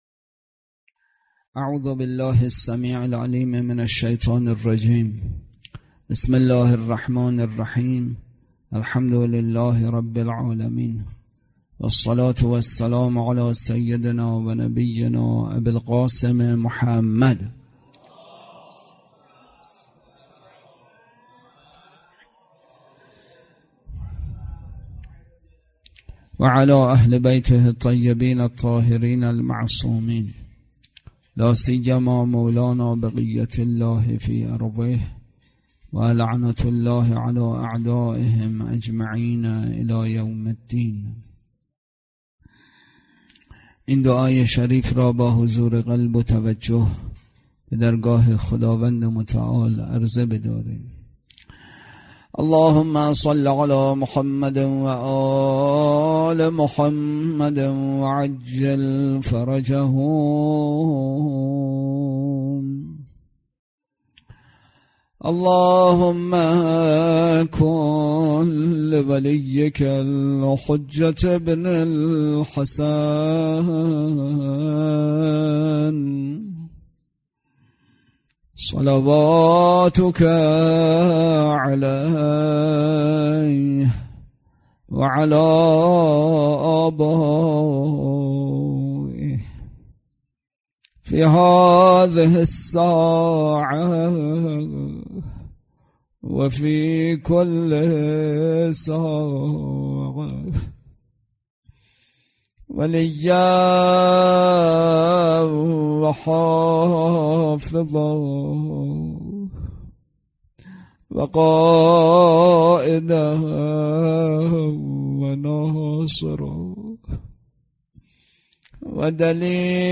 اطلاعات آلبوم سخنرانی
برگزارکننده: مسجد اعظم قلهک